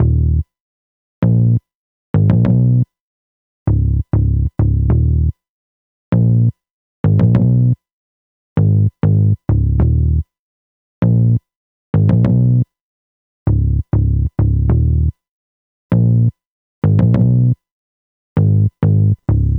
Hands Up - Picked Bass.wav